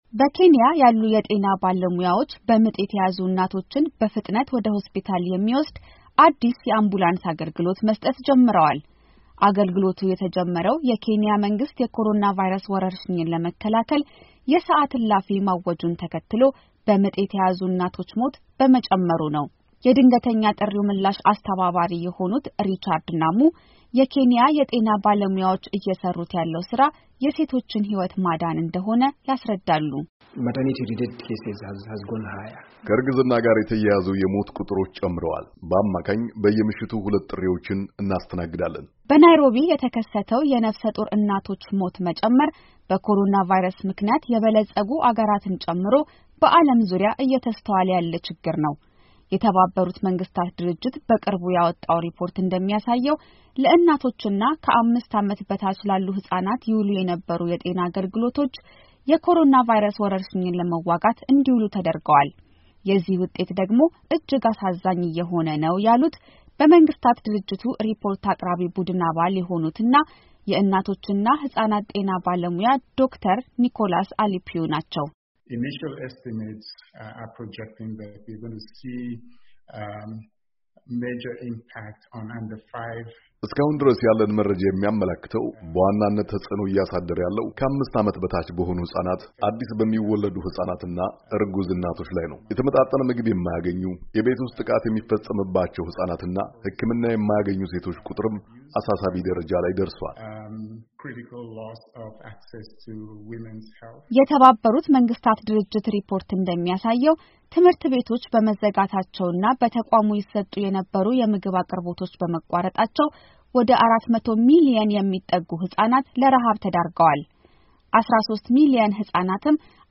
በዓለም ዙሪያ ከግማሽ ሚሊዮን በላይ ህይወት የቀጠፈው የኮሮና ቫይረስ በእናቶችና ህፃናት ጤና ላይ ተጨማሪ ስጋቶች ማስከተሉን የተባበሩት መንግስታት ድርጅት አስታውቋል። በወረርሽኙ ምክንያት ለእናቶችና ህፃናት የሚሰጡ የጤና አገልግሎቶች ሀያ በመቶ በመቀነሳቸው፣ የእናቶች፣ አዲስ የሚወለዱ አና ታዳጊ ህፃናት ሞት ቁጥር መጨመሩም ተነግሯል። ዘገባው